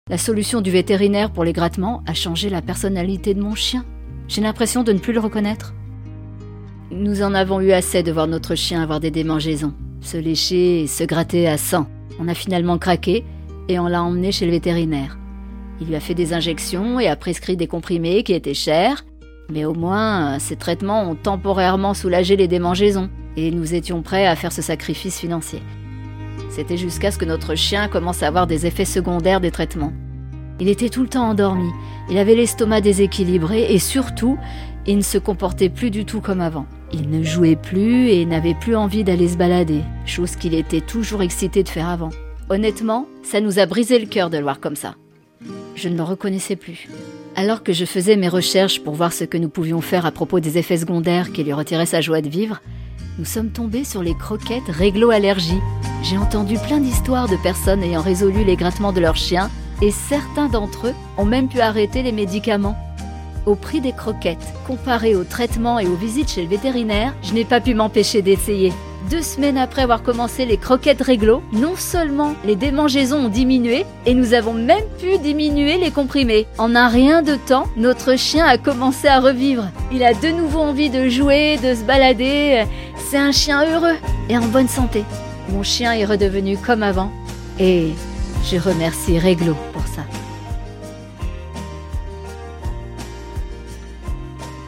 Narration livre audio